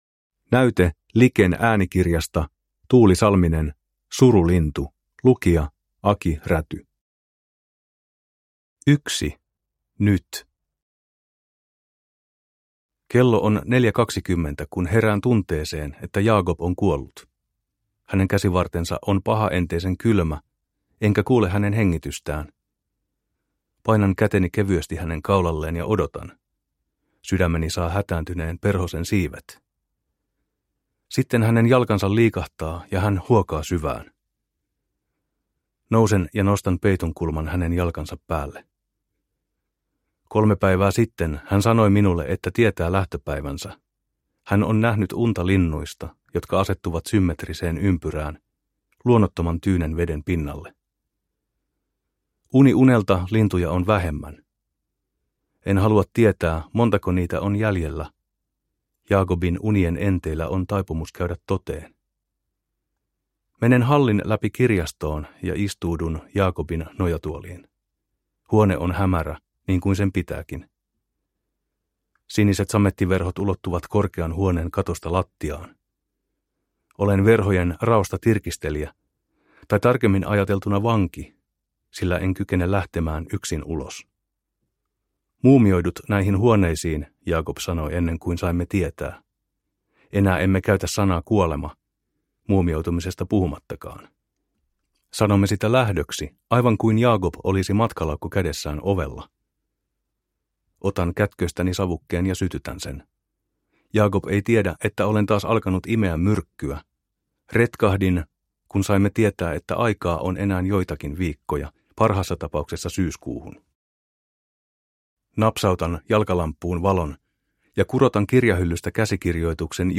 Surulintu – Ljudbok – Laddas ner